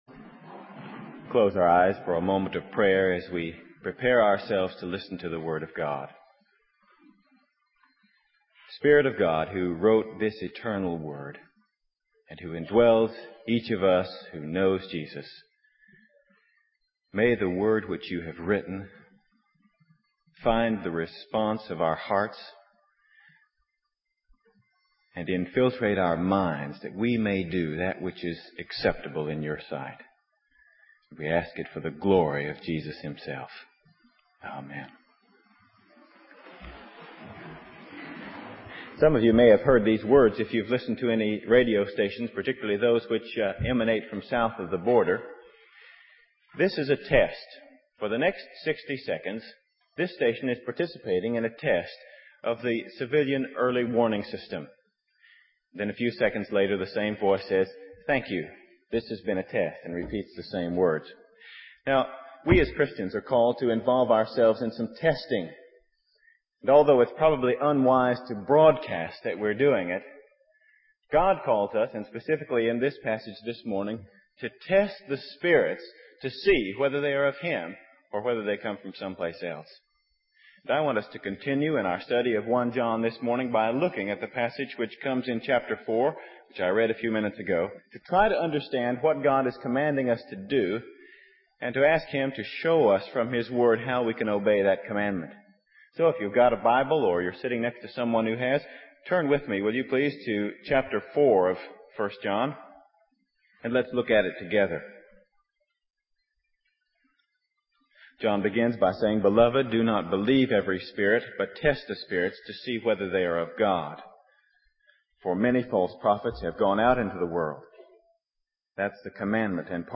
In this sermon, the preacher discusses the conflict between the church, which is indwelt by the Spirit of God, and the world, which is indwelt by the Spirit of error. The preacher emphasizes the importance of knowing how to test the spirits to determine if someone is speaking from God or not.